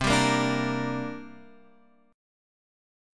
Bb6/C chord